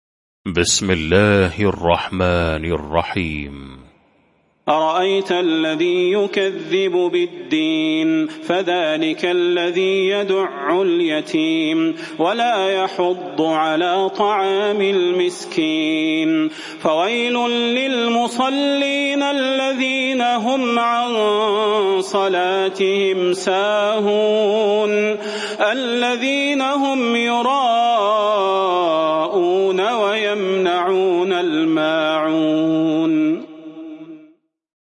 المكان: المسجد النبوي الشيخ: فضيلة الشيخ د. صلاح بن محمد البدير فضيلة الشيخ د. صلاح بن محمد البدير الماعون